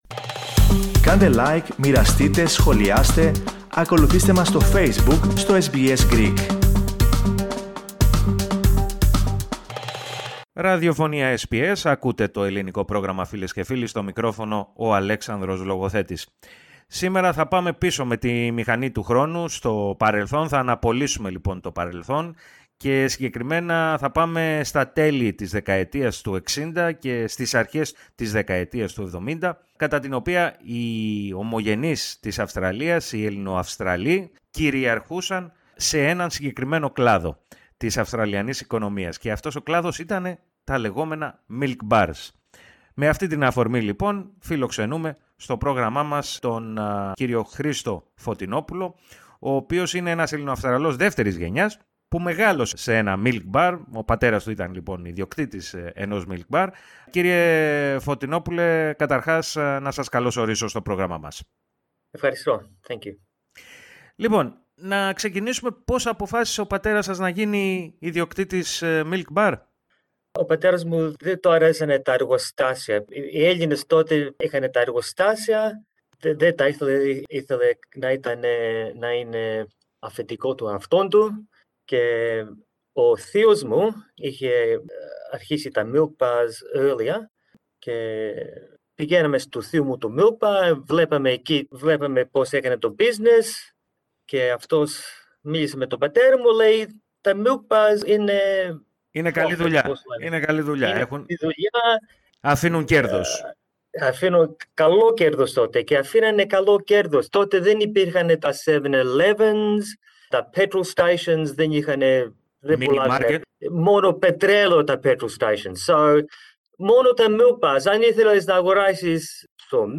Ακούστε ολόκληρη τη συνέντευξη, πατώντας το σύμβολο στο μέσο της κεντρικής φωτογραφίας και ταξιδέψτε με τη «μηχανή του χρόνου», σε εκείνες τις διαφορετικές εποχές της Ελληνοαυστραλιανής κοινότητας.